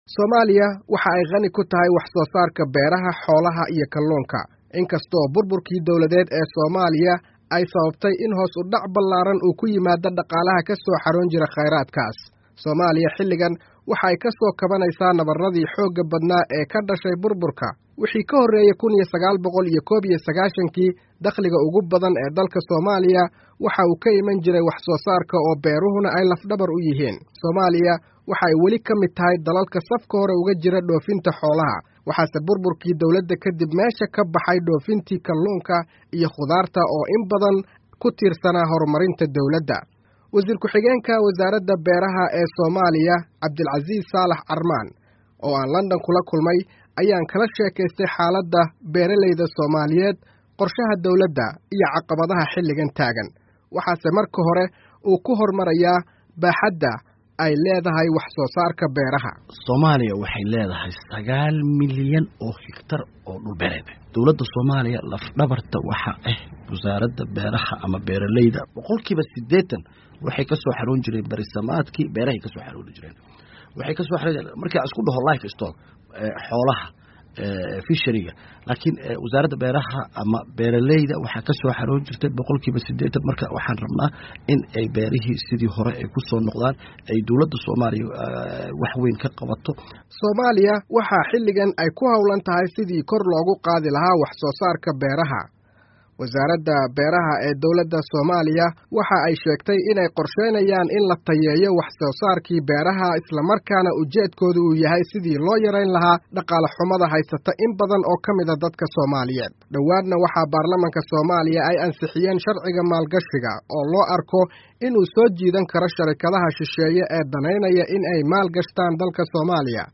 Warbixin: Soomaaliya oo Eedeysay Hay'adda WFP